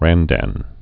(răndăn)